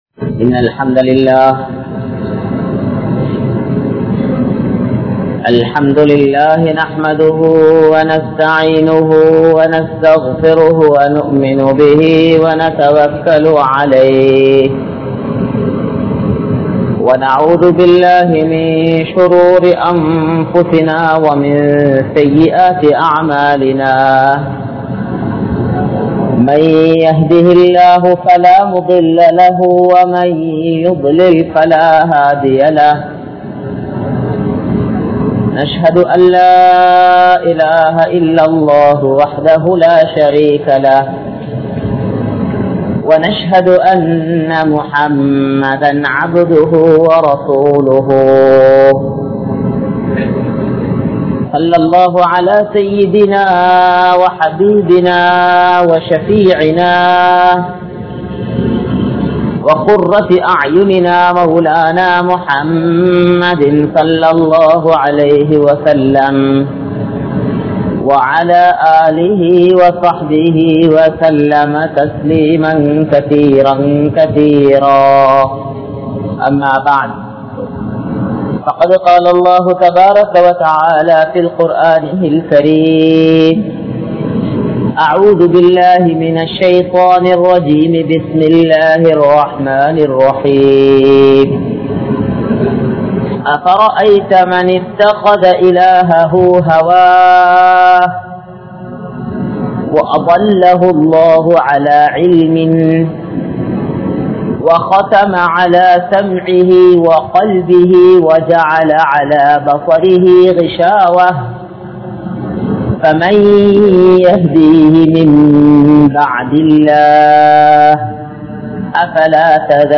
Ullam Seium Paavangal (உள்ளம் செய்யும் பாவங்கள்) | Audio Bayans | All Ceylon Muslim Youth Community | Addalaichenai
Muhiyadeen Jumua Masjidh